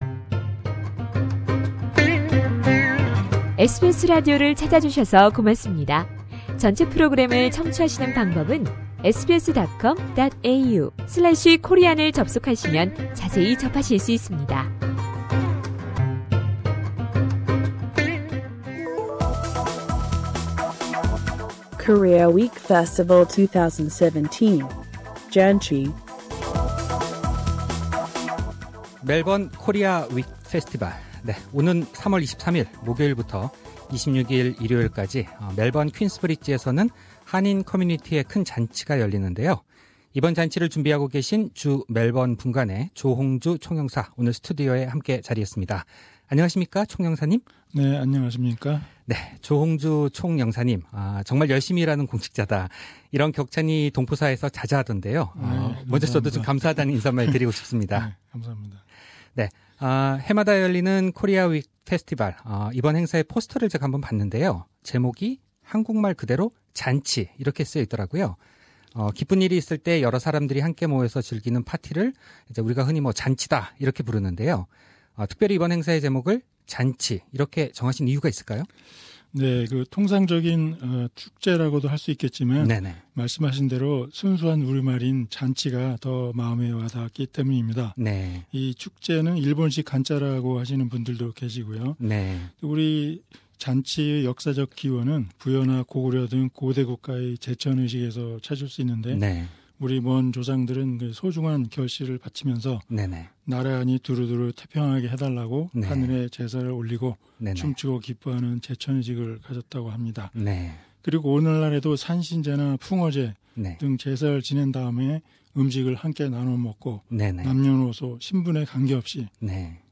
3월 23일 목요일부터 26일 일요일까지 멜번 퀸스 브리지 스퀘어에서는 2017 Korea Week Festival '잔치(JANCHI)'가 열린다. 이번 행사의 하이라이트를 주멜번분관의 조홍주 총영사에게서 들어본다.
이번 잔치를 준비하고 있는 주멜번분관의 조홍주 총영사와 함께 이번 행사에 대해 이야기 나눕니다.